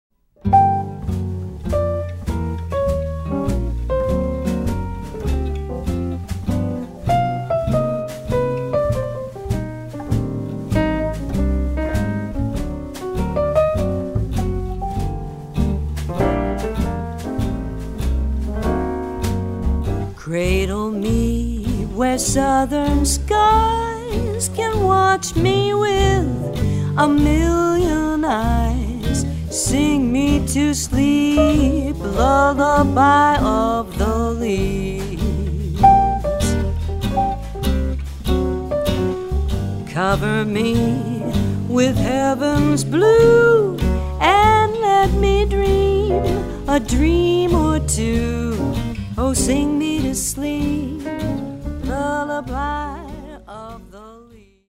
vocals, guitar
trumpet, flugelhorn
piano
bass
drums